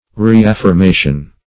(r[=e]*[a^]f`f[~e]r*m[=a]"sh[u^]n)